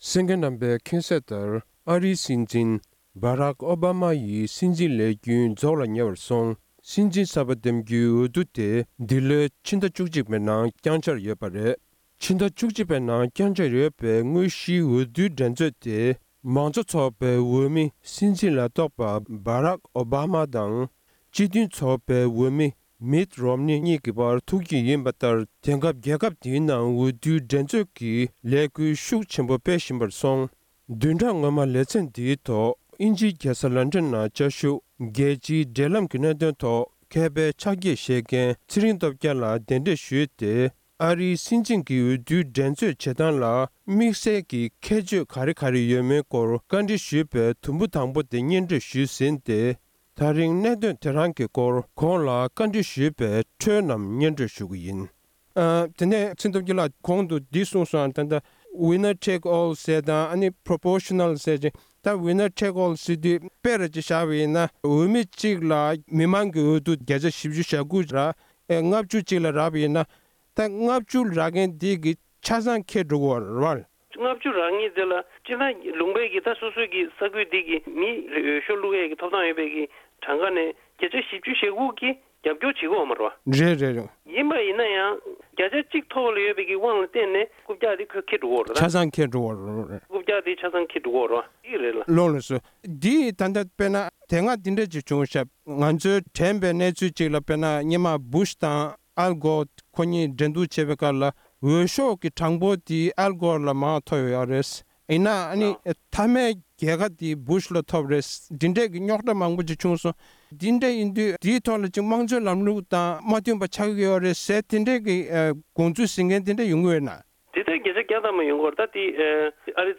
ཨ་རིའི་སྲིད་འཛིན་འོས་བསྡུའི་འགྲན་རྩོད་བྱེད་སྟངས་ལ་དམིགས་བསལ་ཁྱད་ཆོས་གང་དང་གང་ཡོད་པའི་སྐོར་ལ་གནས་འདྲི་ཕྱོགས་སྒྲིག་ཞུས་པའི་དམིགས་བསལ་ལས་རིམ།